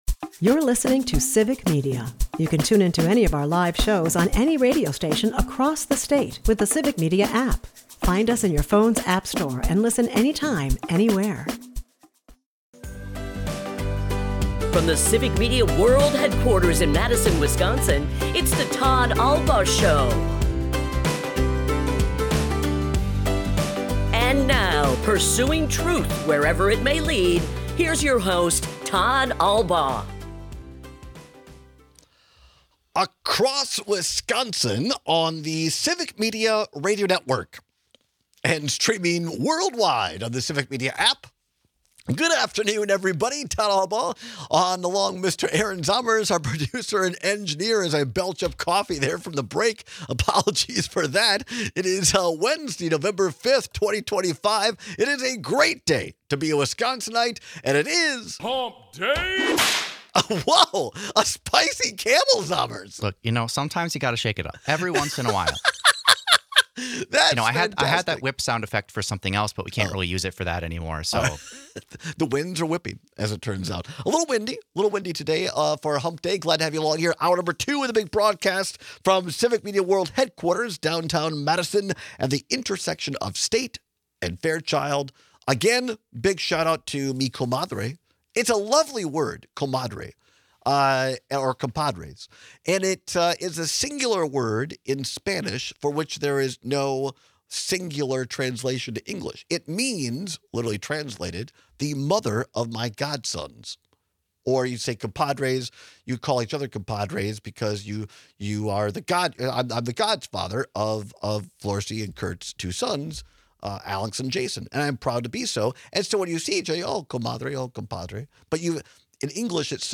We take a lot of calls and texts with your thoughts.